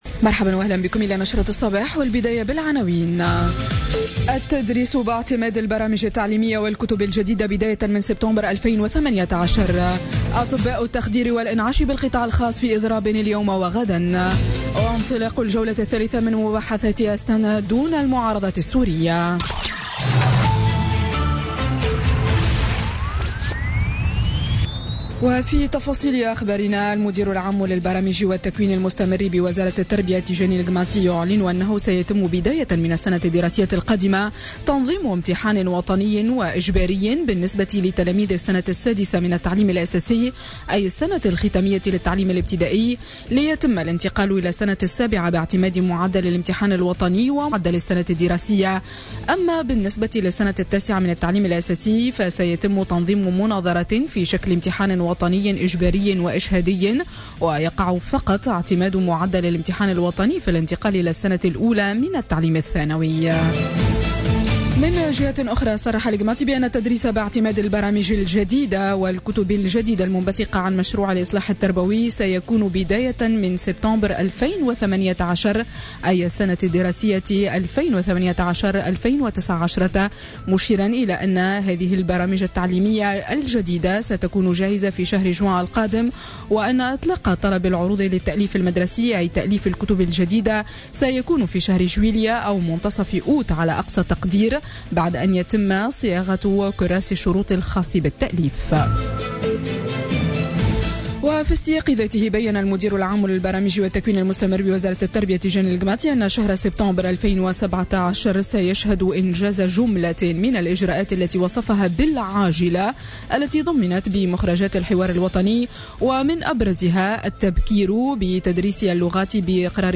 نشرة أخبار السابعة صباحا ليوم الثلاثاء 14 مارس 2017